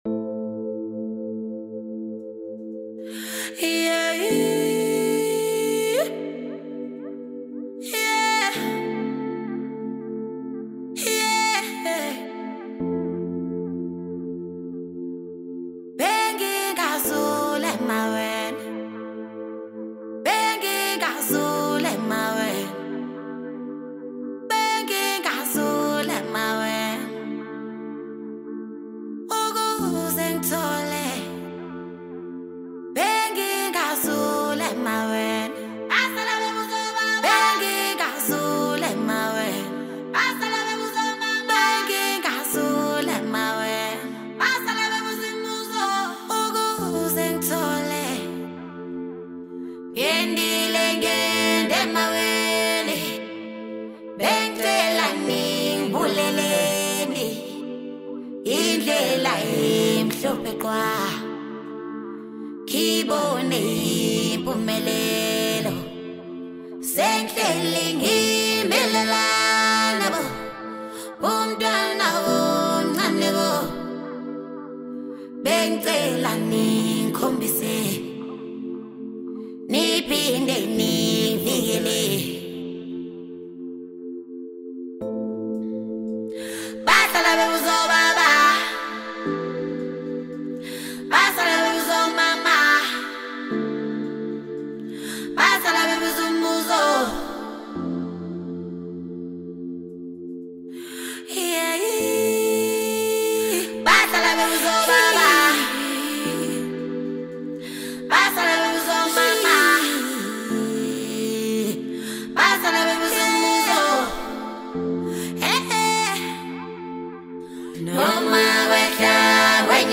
Foreign MusicSouth African
With catchy beats and soulful lyrics